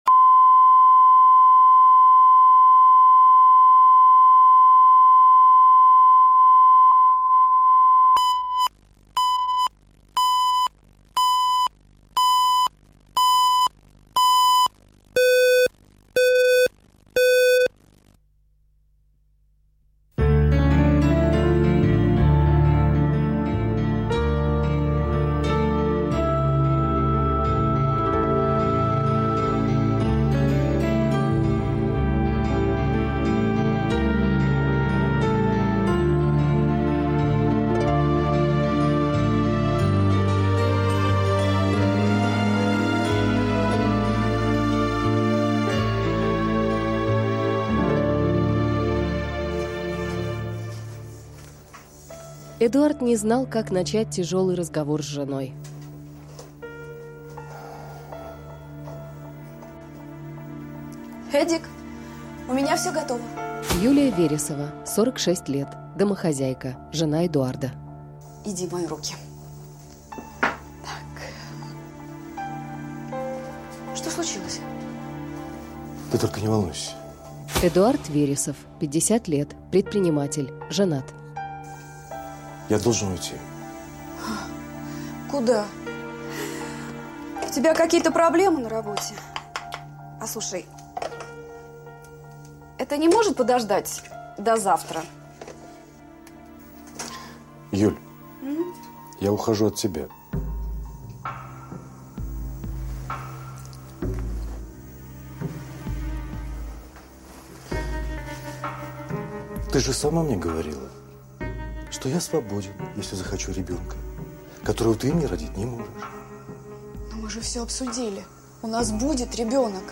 Аудиокнига Растаявший мираж | Библиотека аудиокниг